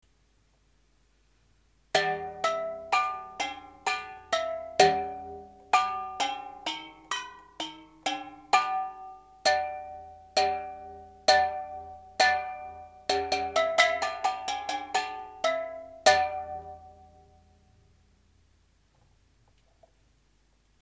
アルミ缶ベルチャイム　＜１９９７年＞
アルミ缶ハンドベルはひとりで演奏するのが大変なので、缶を針金ハンガーにつるして、バチでたたくようにしたものです。
上のアルミ缶ハンドベル同様、缶の音は澄んだ音がしないし、倍音がたくさん聞こえるので、演奏する時は、だれでもよく知っている曲を、「今から○○を演奏します。」と言ってからはじめないと、なかなかそのように聴いてもらえません。